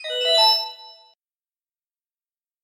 Звуки включения устройств
Аппарат включили